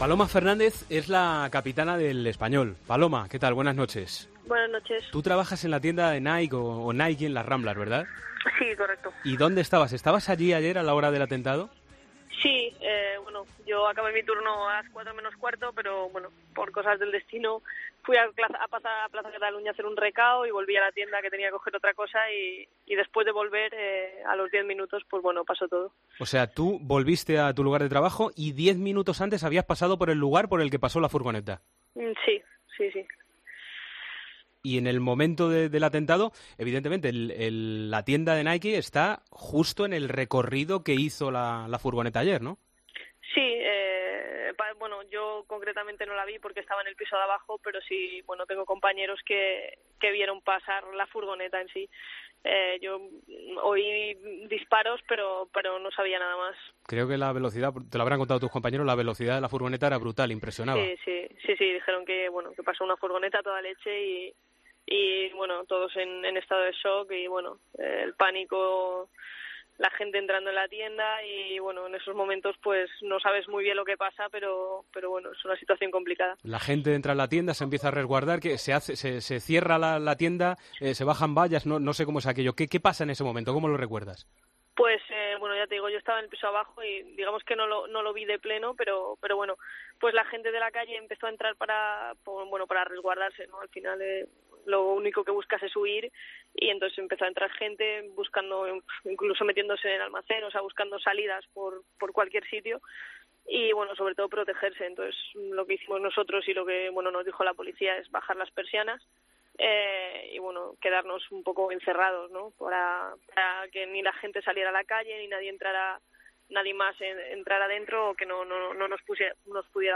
Entrevistas en El Partidazo de COPE